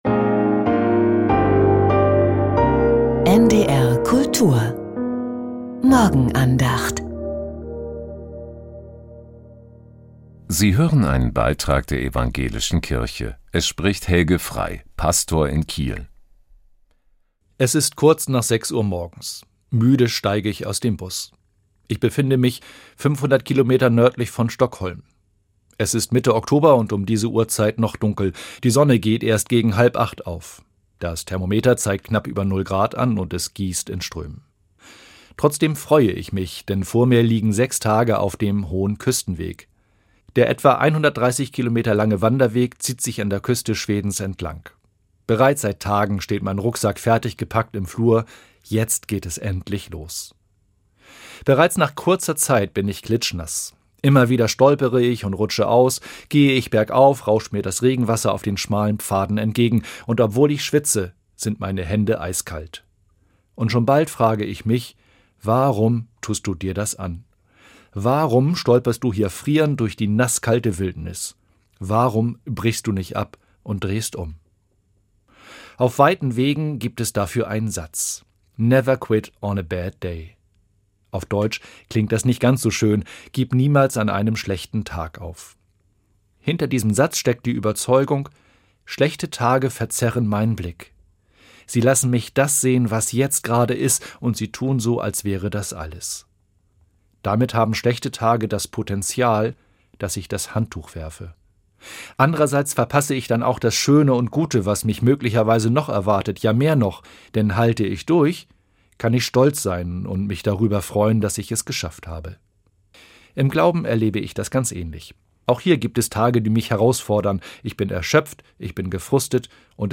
Die Andachten waren auf NDR Info und NDR Kultur zu hören.